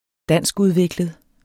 Udtale [ -uðˌvegləð ]